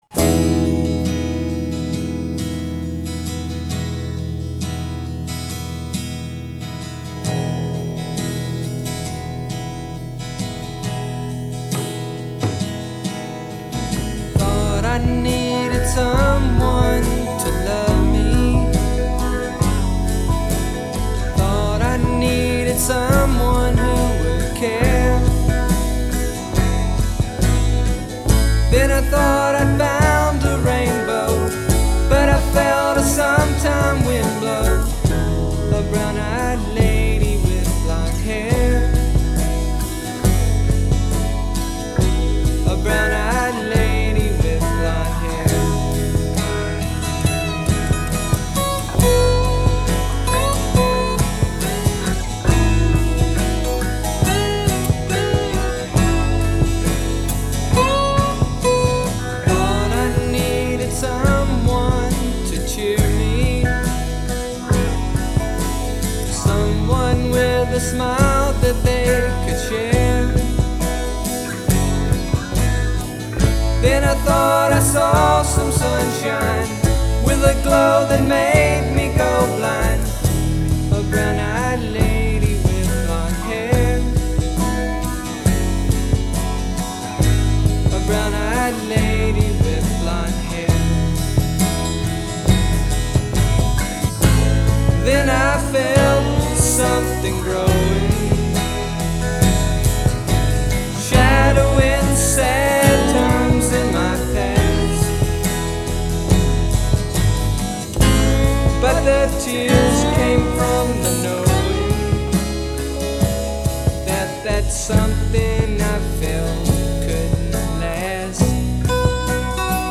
shimmering, unbuttoned-collar collection of
breezy soft rock and gorgeous sunset